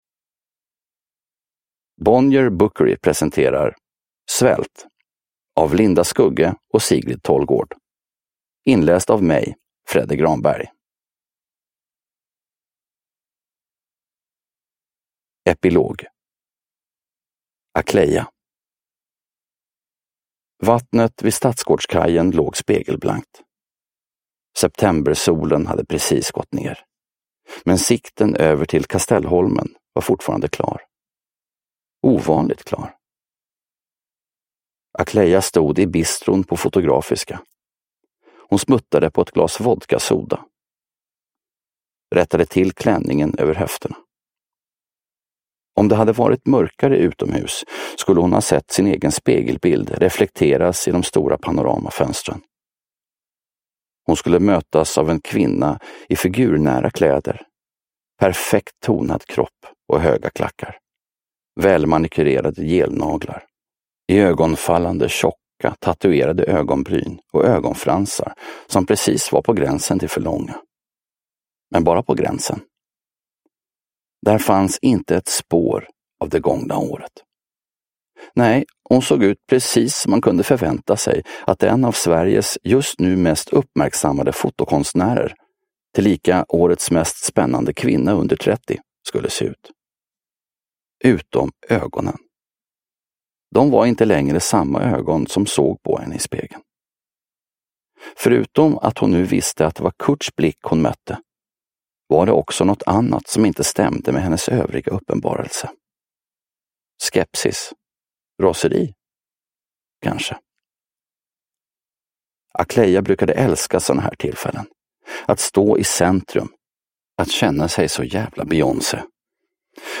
Svält. S1E10 – Ljudbok – Laddas ner
Uppläsare: Fredde Granberg